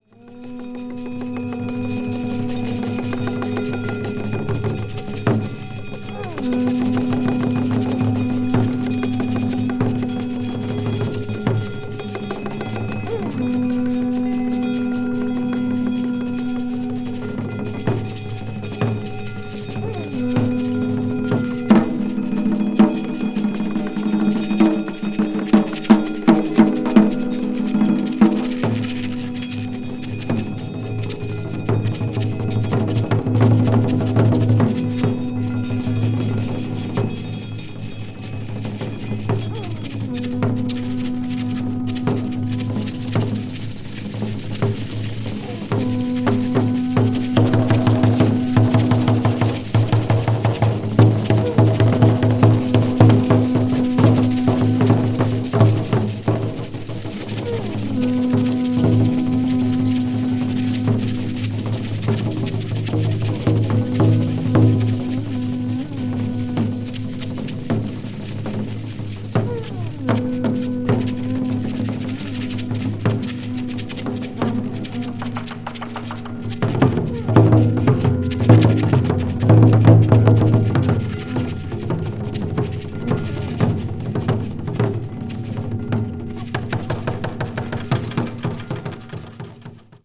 media : EX+/EX(some slightly noises.)